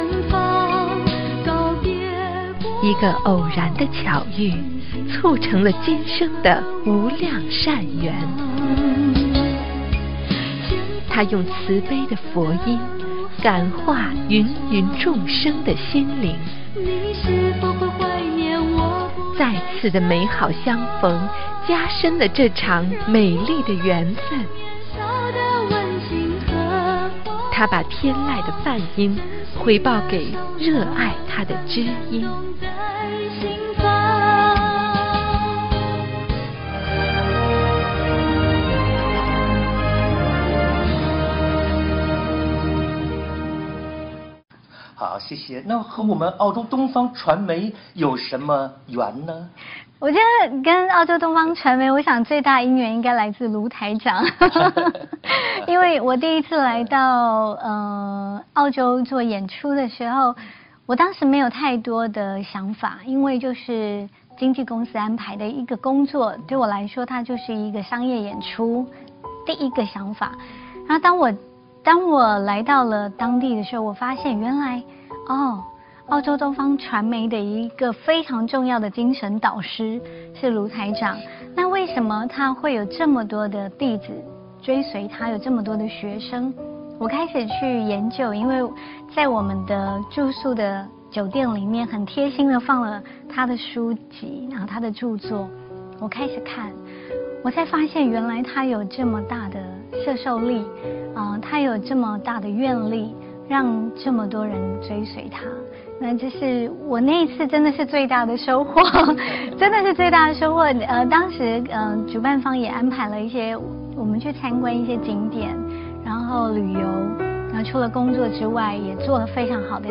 音频：2015澳大利亚东方广播电视台8周年-孟庭苇专访谈及恩师